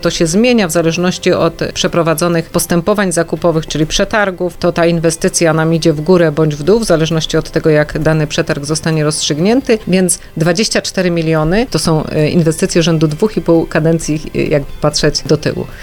Jak powiedziała w Magazynie Samorządowym wójt Ewa Markowska – Bzducha, ta kwota jest olbrzymia jak na nasze możliwości budżetowe i nie jest ostateczna: